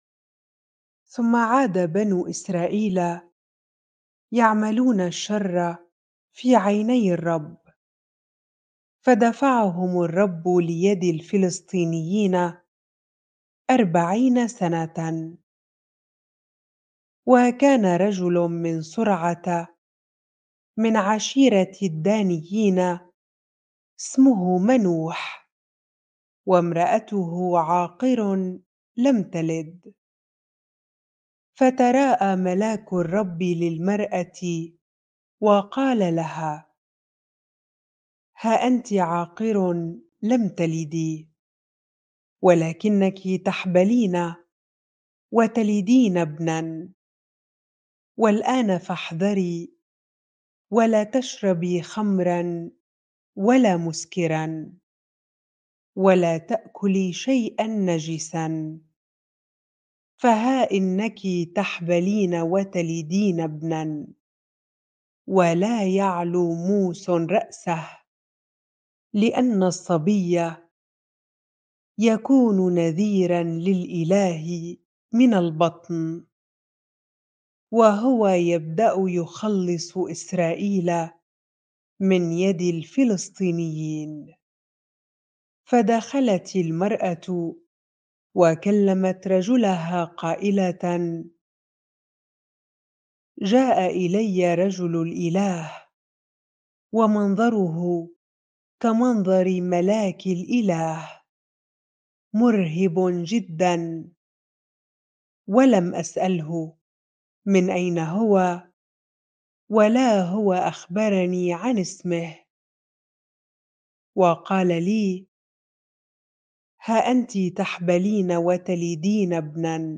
bible-reading-Judges 13 ar